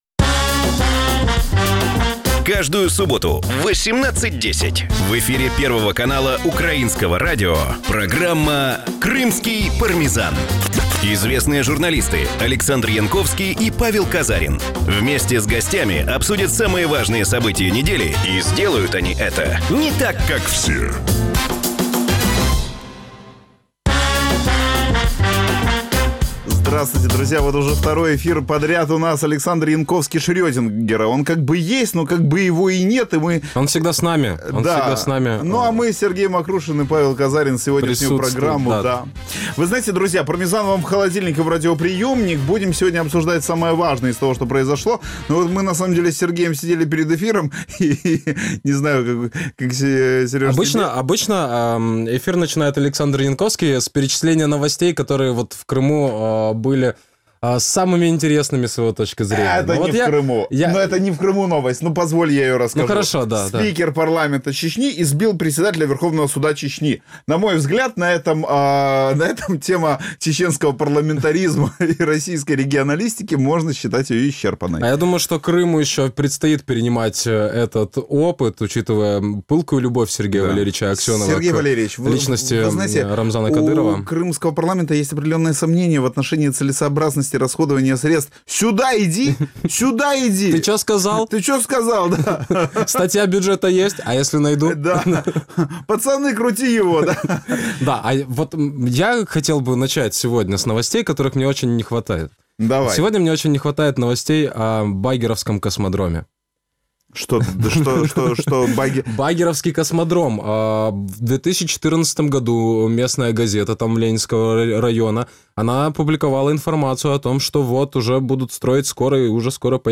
обговорюють головні події тижня, що минає в Україні, Росії та в Криму. Чи запровадить Наталія Поклонська нові корупційні види спорту в Держдумі, у кого вкрав гроші Володимир Константинов і за скільки мільйонів років Крим фізично возз'єднається з Росією - відповіді на ці питання в нашій програмі. Програма звучить в ефірі Радіо Крим.Реалії. Час ефіру: 18:10 - 18:40.